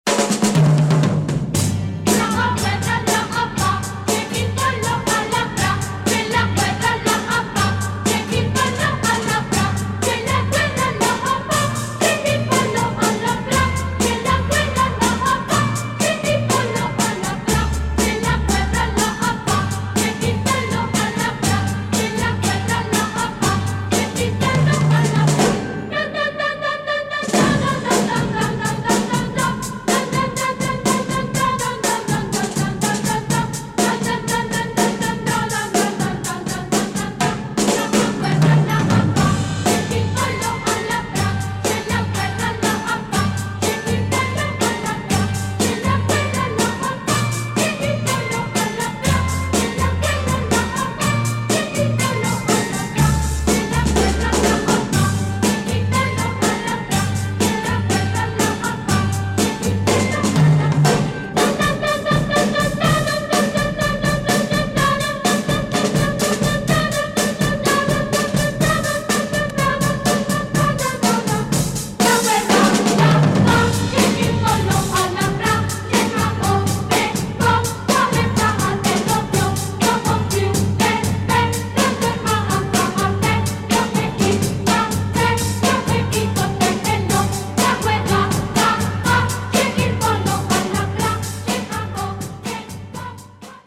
long suite
terrific pop beat
spooky female choir and heavy drums